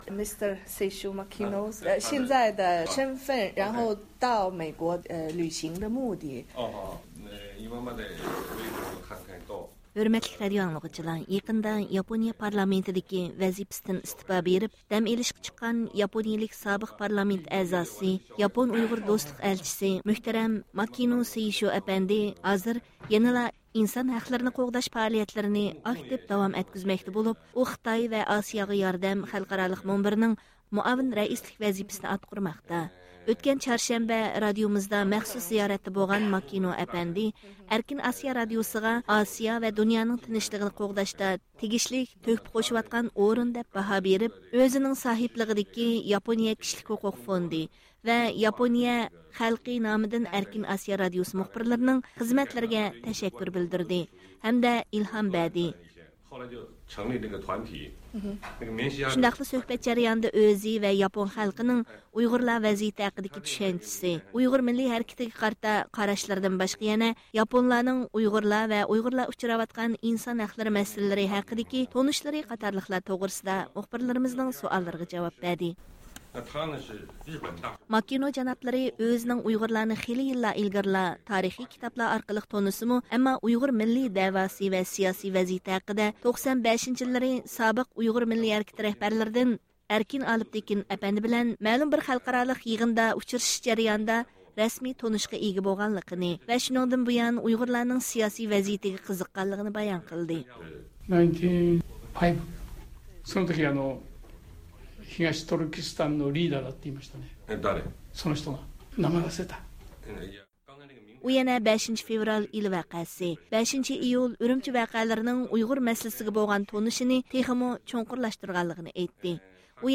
ياپونىيەنىڭ سابىق پارلامېنت ئەزاسى ماكىنو سېيشۇ ئەپەندى، 19 ئۆكتەبىر ئەركىن ئاسىيا رادىيومىزدا زىيارەتتە بولغان ئىدى.
ئۇ مۇخبىرلىرىمىز بىلەن ئۇيغۇرلار ۋەزىيىتى ۋە ئۇلار ئۇچراۋاتقان ئىنسان ھەقلىرى مەسىلىلىرى توغرىسىدا مەخسۇس سۆھبەتتە بولدى.